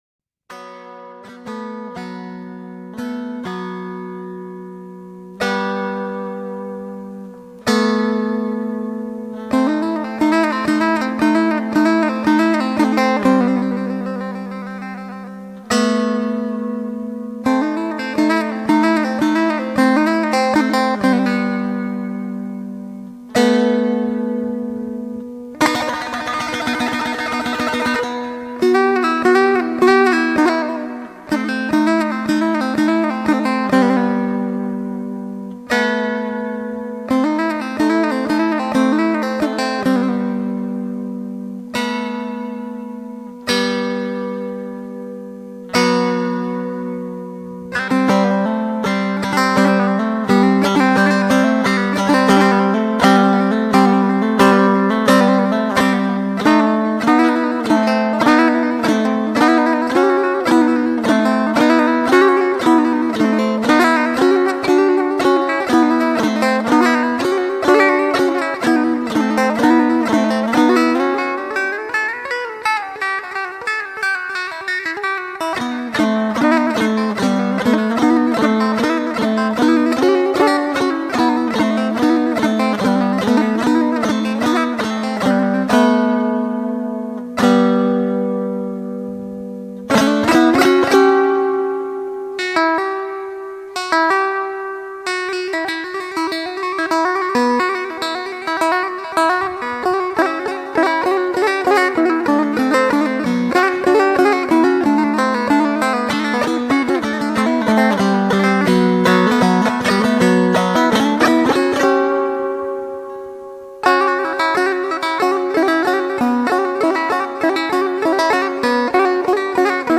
Türkmen Traditional Music